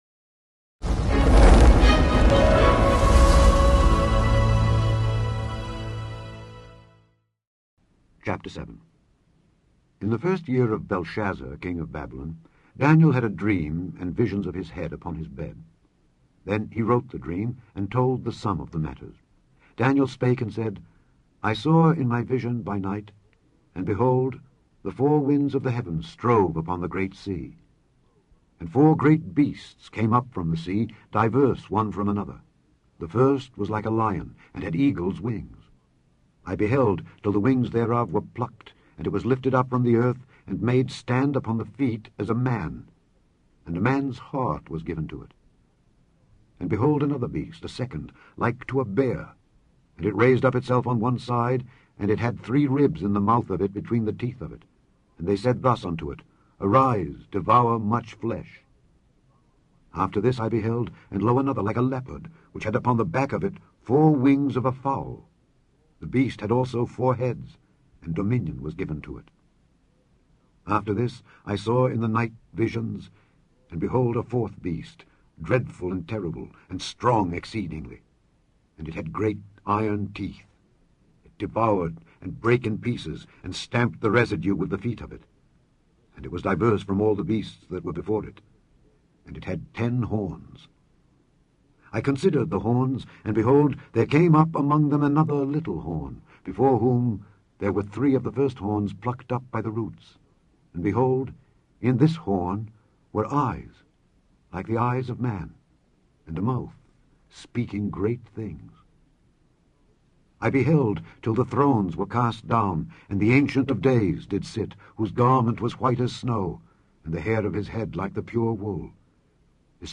In this podcast you can listen to Alexander Scourby read Proverbs 17-18.